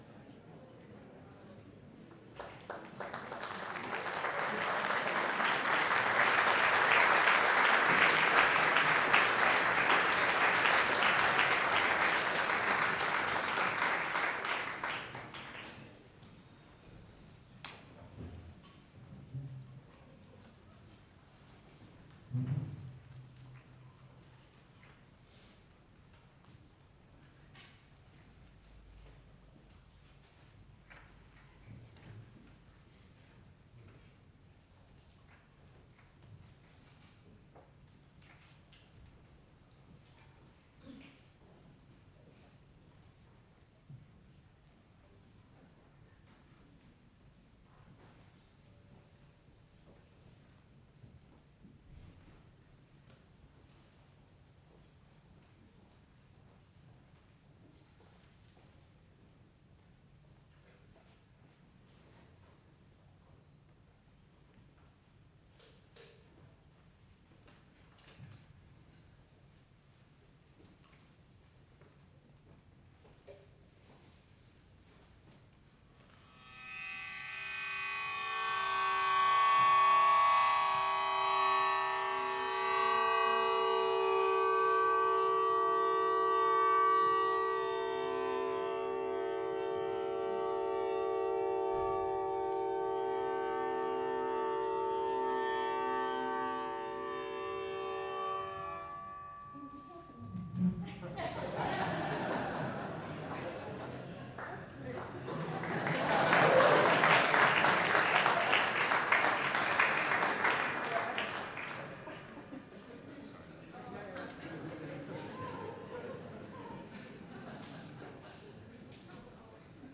cello, electric guitar, electronics